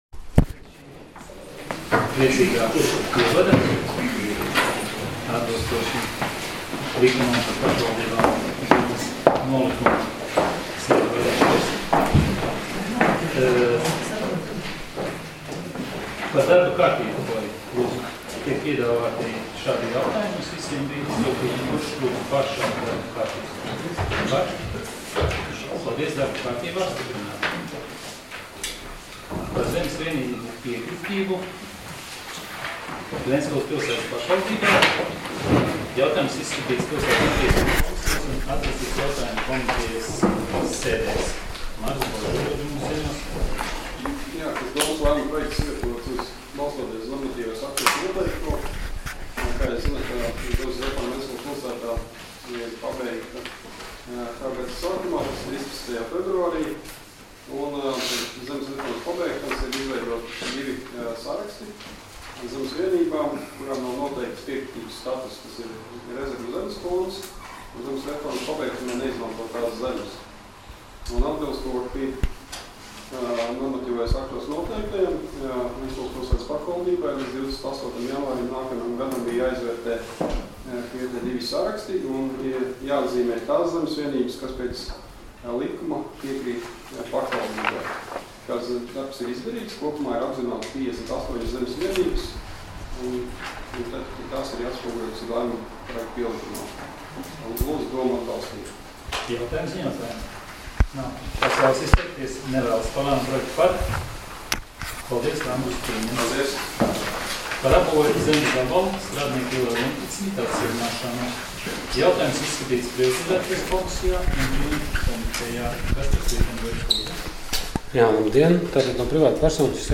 Domes sēdes 07.12.2017. audioieraksts